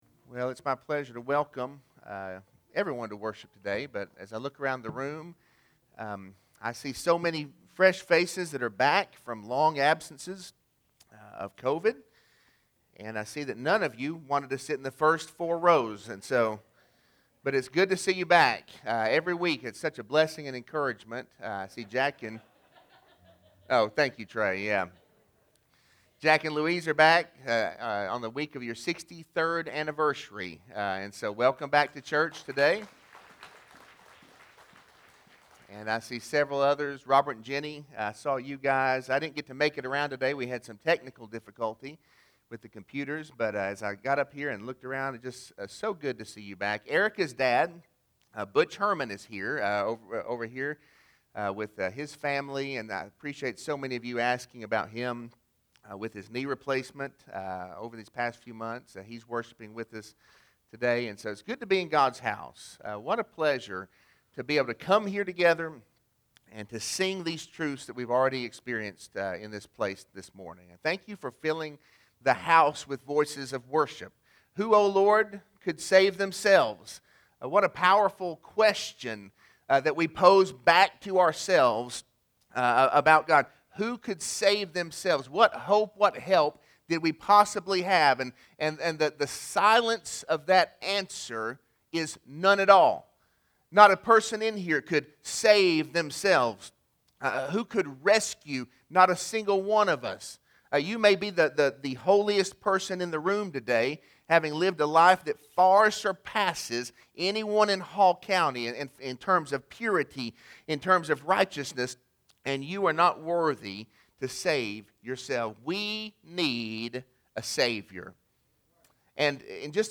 Sermon-3-21-21.mp3